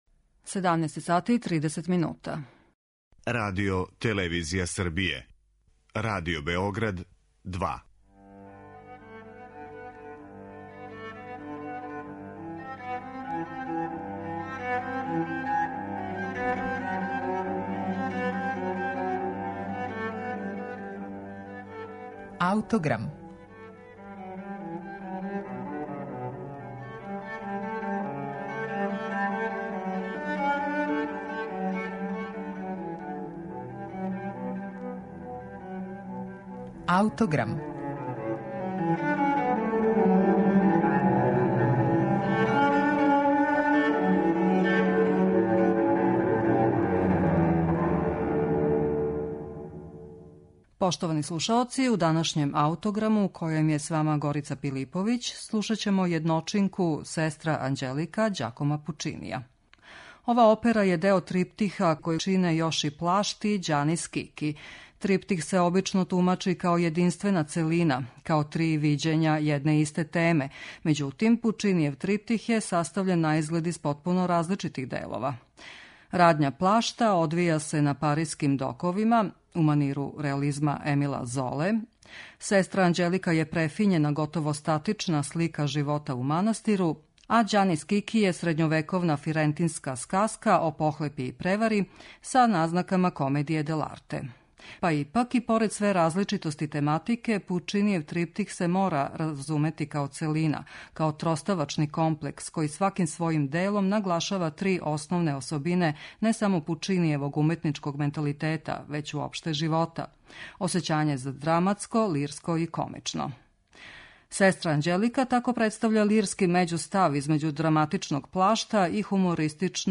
Лирски део триптиха Ђакома Пучинија